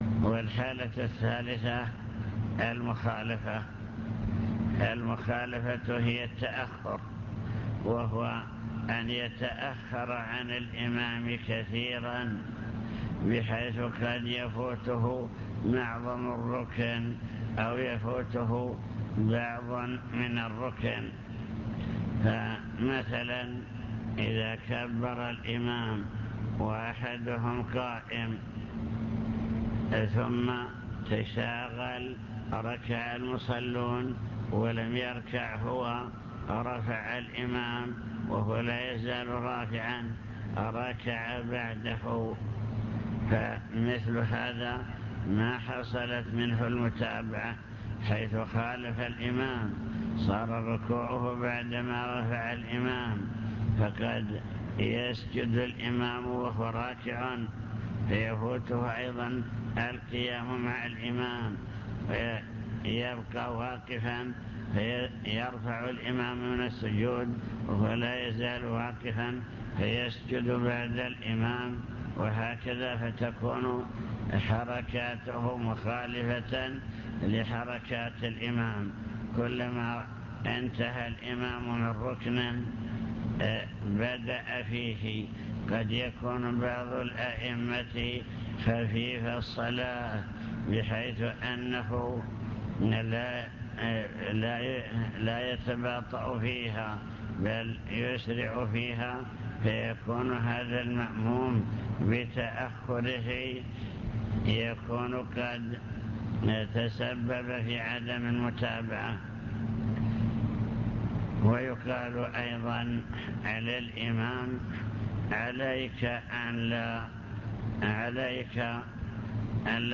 المكتبة الصوتية  تسجيلات - محاضرات ودروس  محاضرة في بدر بعنوان: وصايا عامة حالات المصلين خلف الإمام